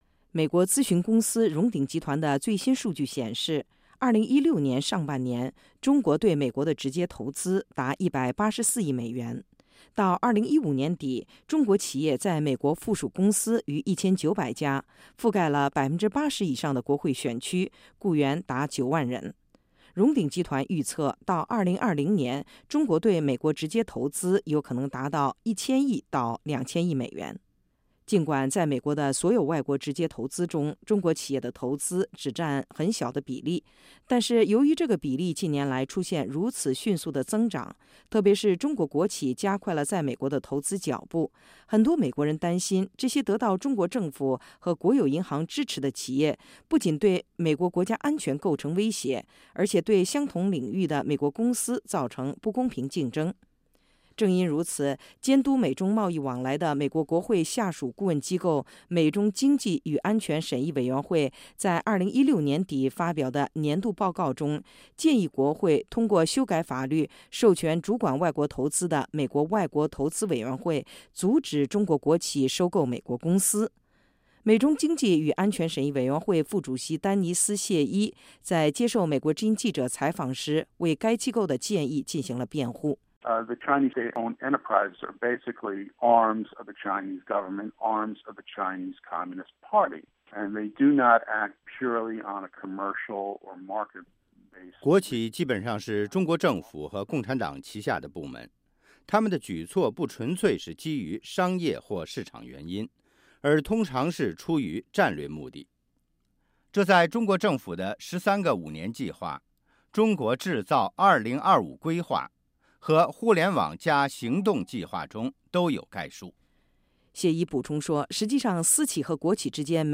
中国企业，特别是国企近年来大举收购美国公司的举措，在美国上下引起不安，特别是在川普走马上任的前夕，各方对新政府上台后将如何应对来自中国的投资挑战众说纷纭。 “美国经济与安全审议委员会”的负责人在接受美国之音的采访时维持该机构向美国国会提出的阻止中国国企收购美国公司的建议，并指出中国国企的行为通常是出于战略目的，而非商业利益。